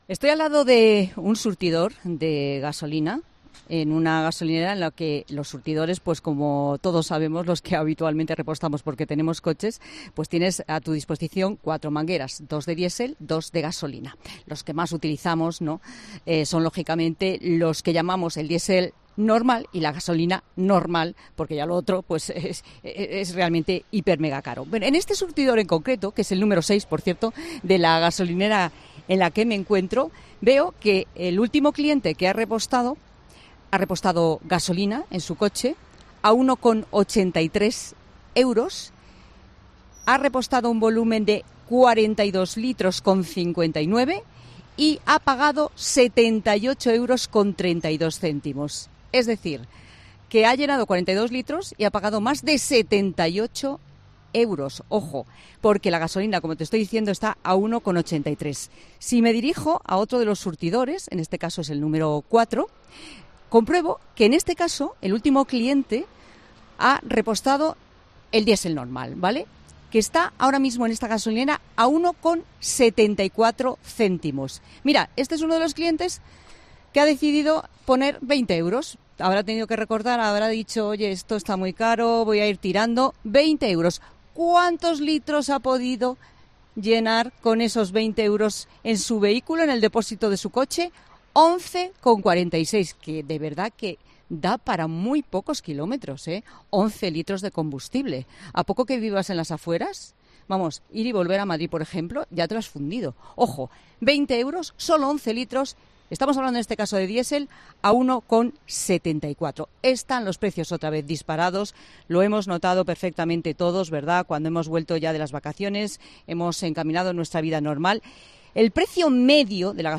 se ha trasladado hasta una gasolinera en Madrid para contarnos desde allí la situación en la que los consumidores nos encontramos con respecto a los carburantes.